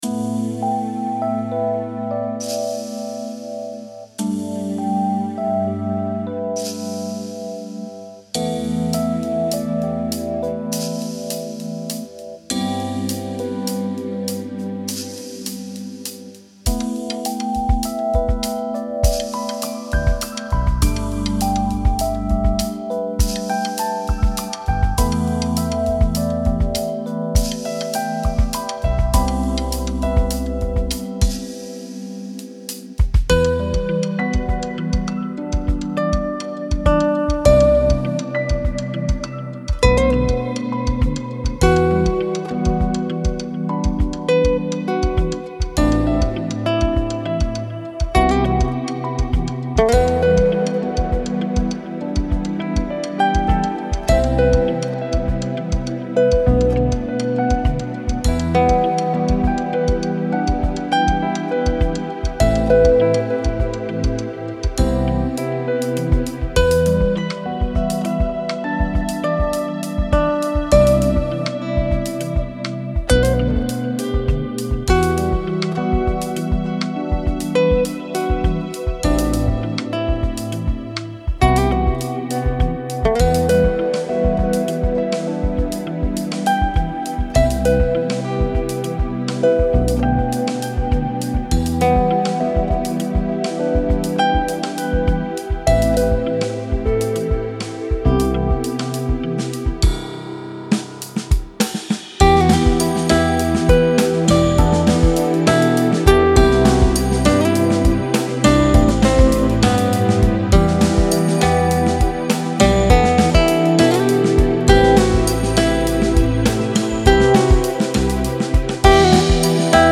Wine And Almond (Instrumental
) В рамках недавно созданного проекта наваяли с гитаристом вот такую ненавязчивую композишку )...